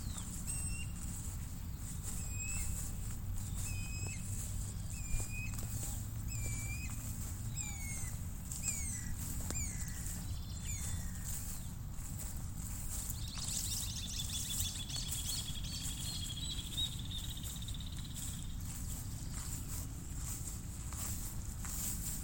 Anu-branco (Guira guira)
Nome em Inglês: Guira Cuckoo
Detalhada localização: Parque 9 de Julio y alrededores
Condição: Selvagem
Certeza: Gravado Vocal